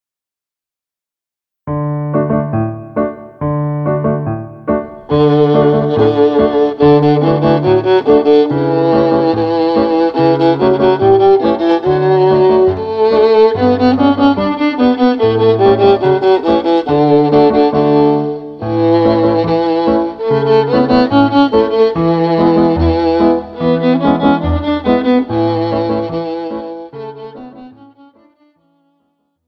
Besetzung: Viola